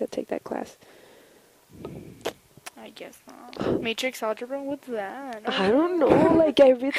Functions of Bookended Narrow-Pitch-Range Regions
4. Complaining, Spanish style